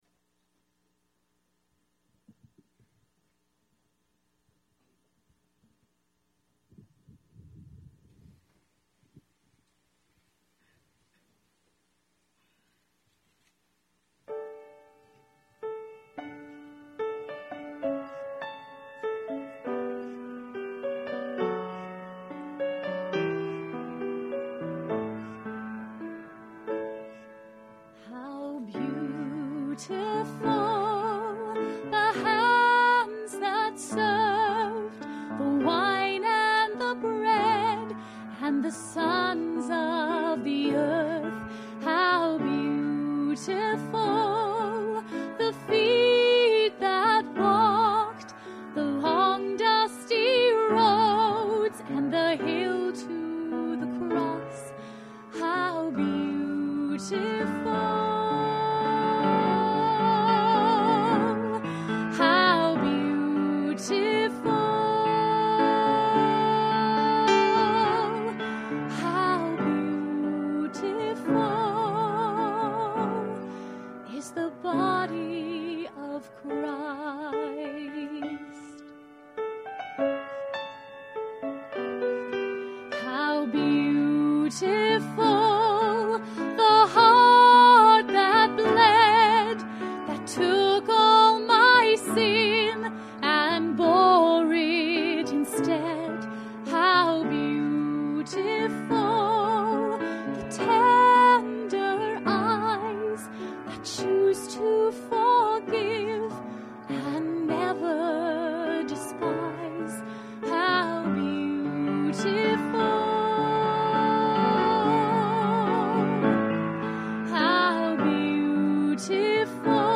I’ve been meaning to post this since the Easter Service it was recorded during, but I seem to keep forgetting to do it when I sit down at the computer.
piano
I made on quick on-the-fly recording of the performance and so here